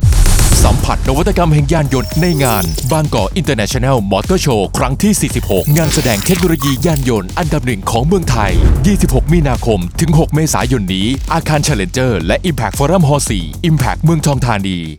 Radio Spot Motorshow46 15วิ